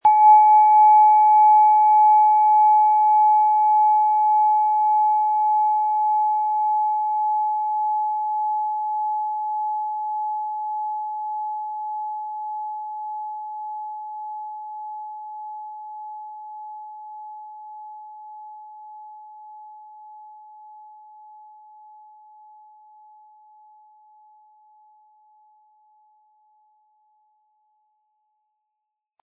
Japanische Klangschale Solfeggio 852 Hz - innere Führung
Die japanische Klangschale mit 852 Hertz entfaltet einen klaren, durchdringenden Ton, der Bewusstsein öffnet und innere Führung stärkt.
Sorgfältig gearbeitete japanische Klangschalen mit 852 Hz zeichnen sich durch Reinheit und Fokussierung im Ton aus. Der Nachhall wirkt hell und weit, öffnet den Raum für Einsicht und geistige Klarheit.
MaterialBronze